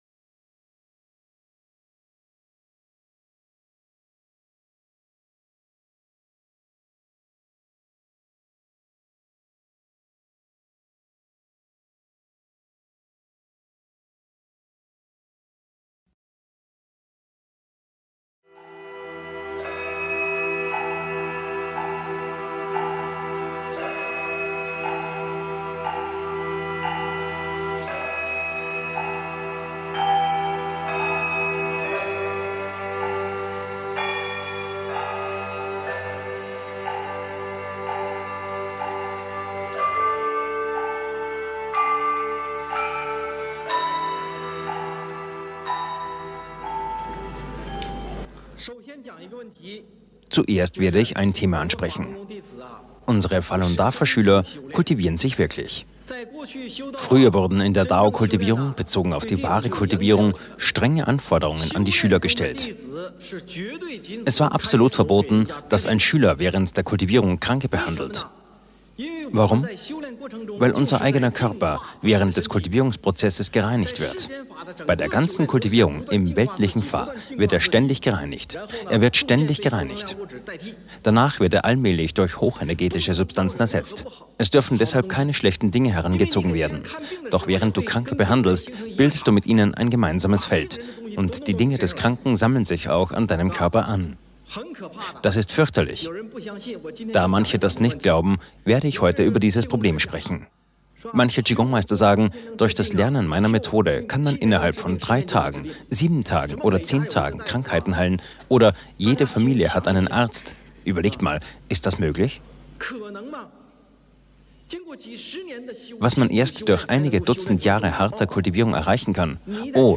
Deutscher Sprecher 2021